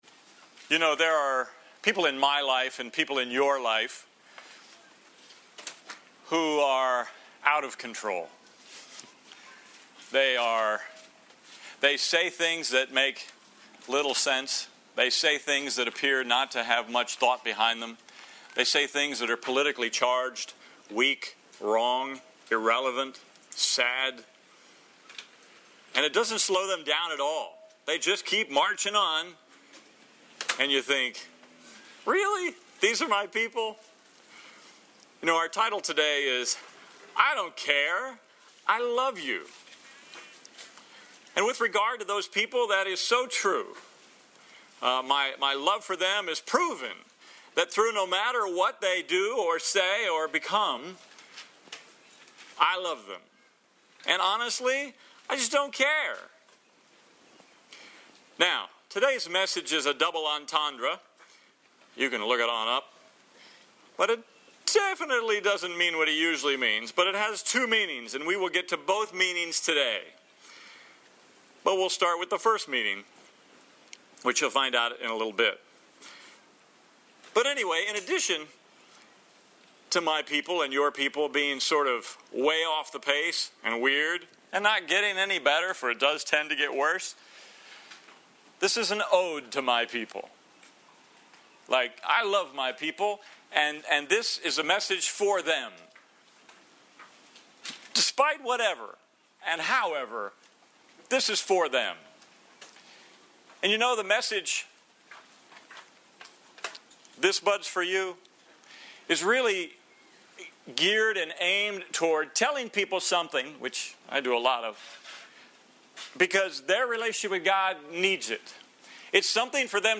This message features the reading and preaching of the following Scriptures: Acts 14:16-17 Acts 17:30-31 Instructions: To download on a Mac, control-click the message link below and select a download option.